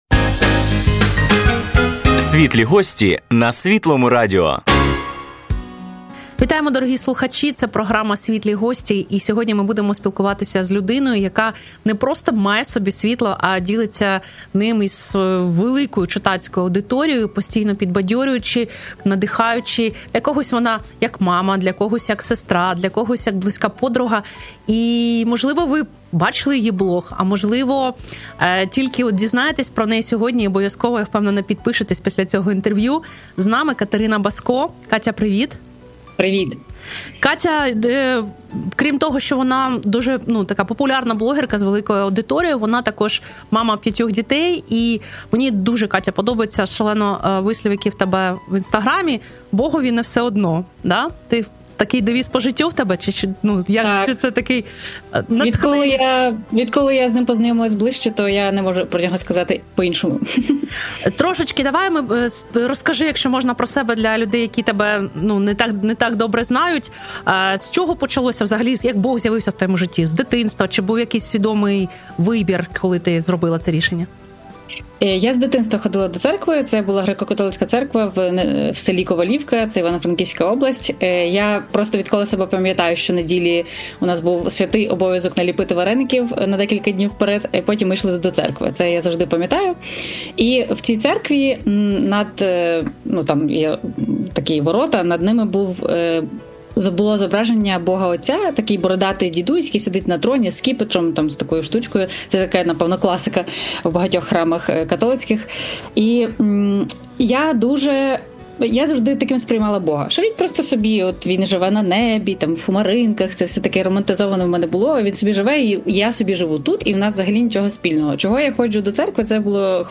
Слухайте надихаючу розмову на «Світлому» про сучасних і біблійних жінок, жіночу посвяту сім’ї, любов до дітей та щиру...